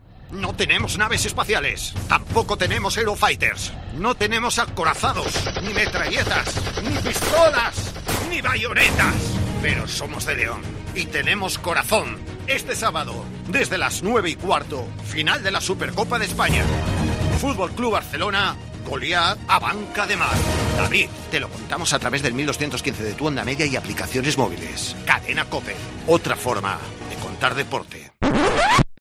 Escucha la cuña promocional del partido Barsa-Abanca Ademar el día 04-09-21 a las 21:30 h en el 1.215 OM